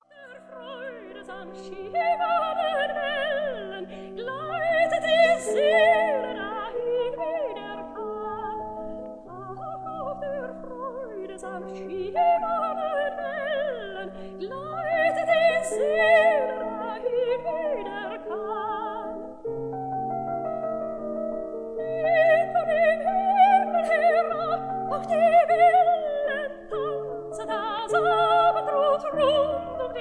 soprano
piano
Sofiensaal, Vienna